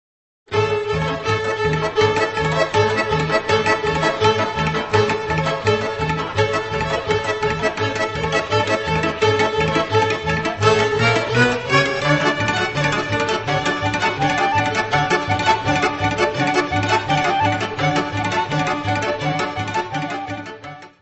Área:  Tradições Nacionais